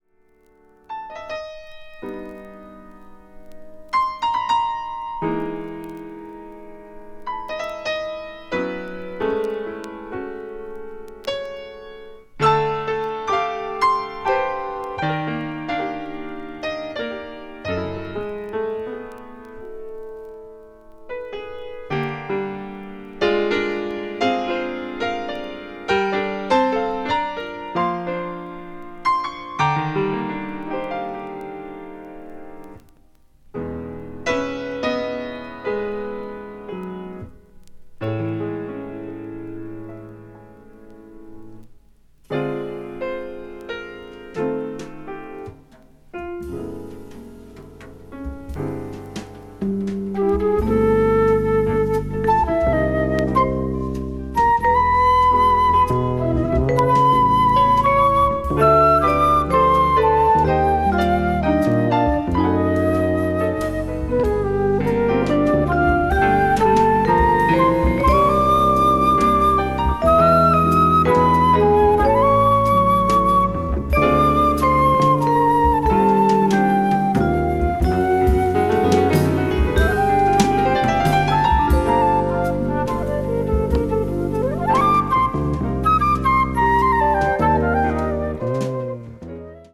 media : EX-/EX-(some slightly noises and some click noises.)
contemporary jazz   modal jazz   post bap   spritual jazz